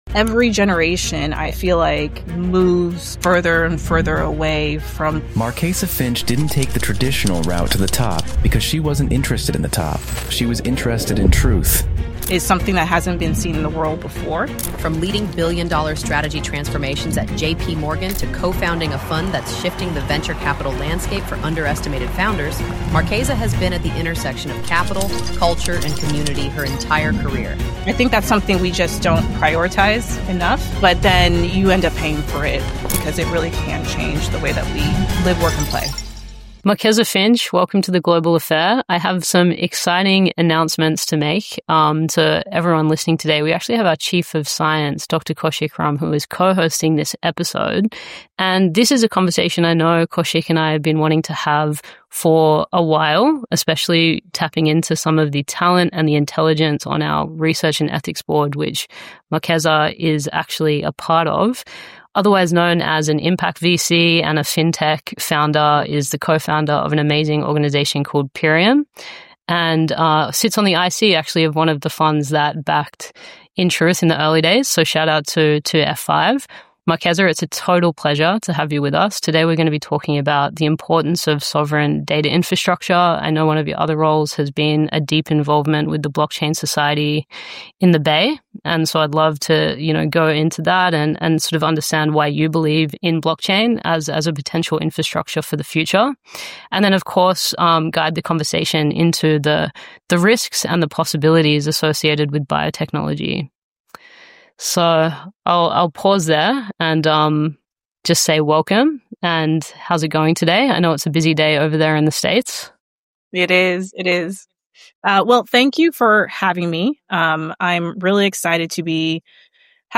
This isn’t a polite panel. It’s a bare-knuckled conversation about what’s really at stake as AI, blockchain, and emotional tech collide.